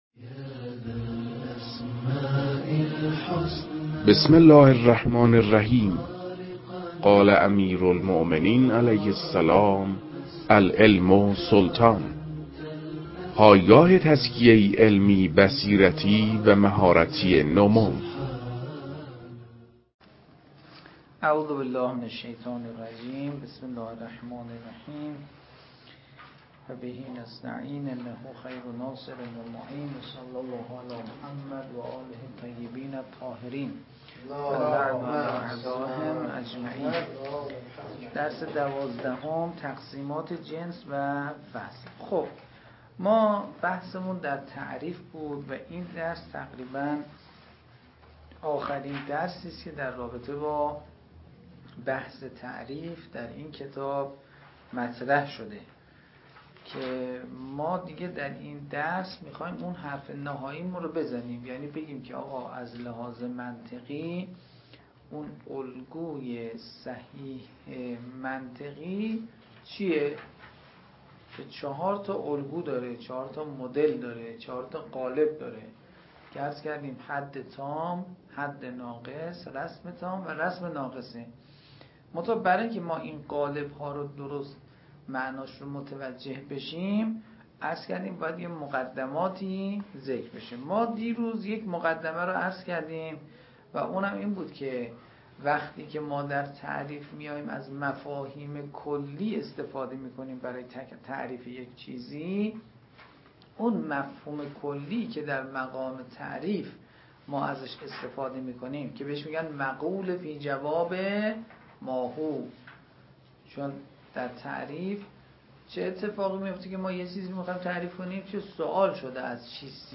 در این بخش، کتاب «درآمدی بر منطق» که اولین کتاب در مرحلۀ آشنایی با علم منطق است، به صورت ترتیب مباحث کتاب، تدریس می‌شود.